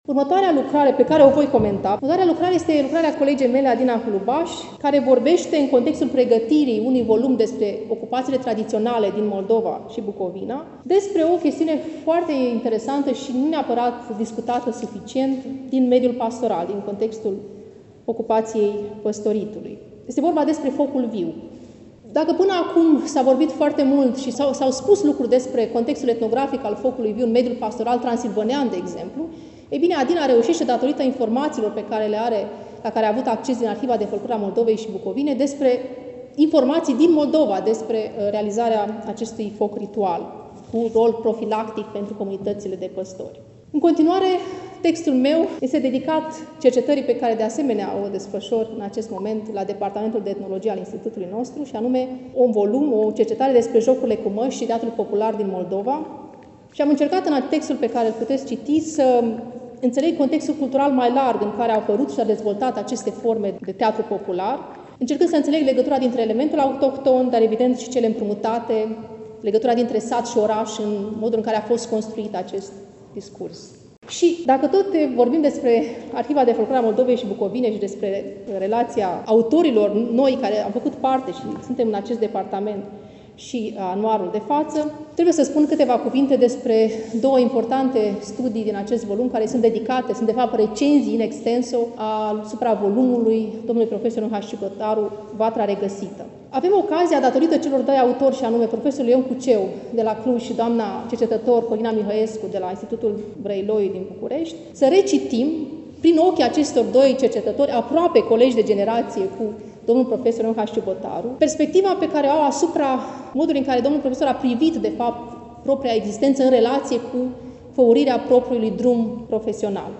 Stimați prieteni, vă reamintim că relatăm de la prezentarea „Anuarului Muzeului Etnografic al Moldovei”, Nr. XXII, lansat, nu demult, la Iași, în Sala „Petru Caraman” din incinta Muzeului Etnografic al Moldovei, Palatul Culturii.